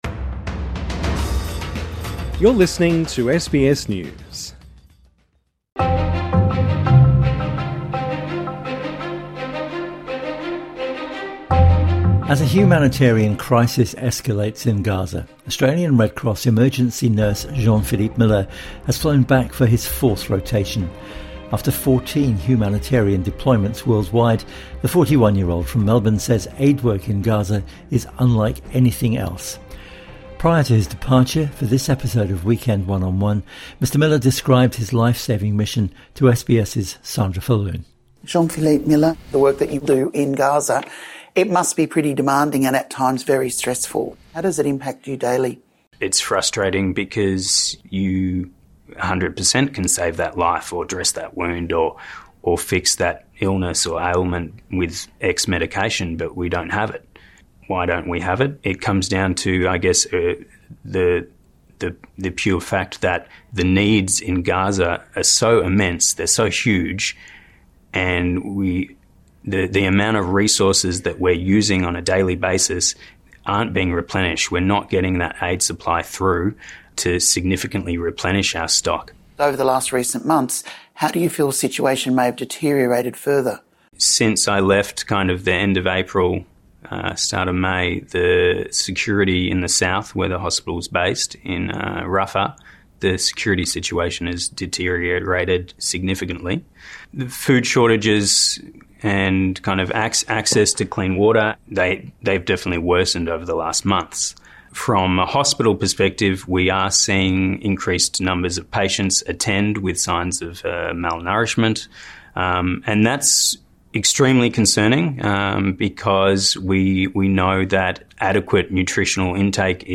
INTERVIEW: Why this emergency nurse is putting his life on the line – again – in Gaza